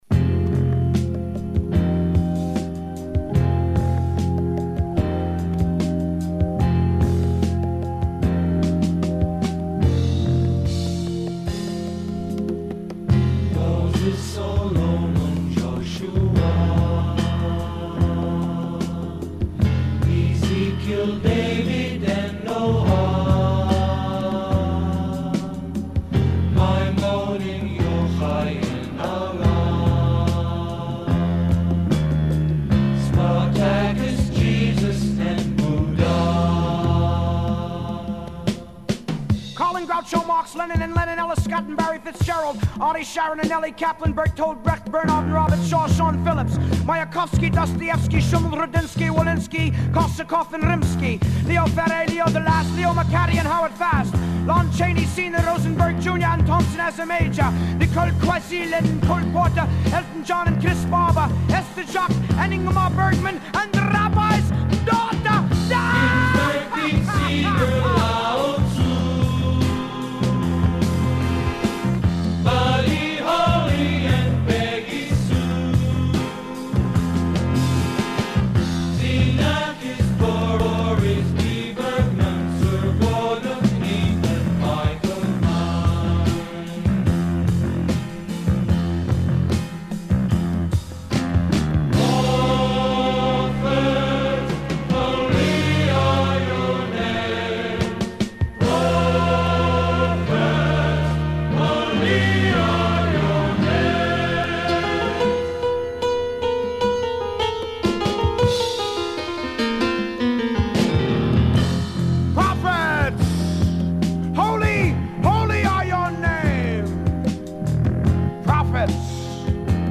heavy breaks with bass-drums-flute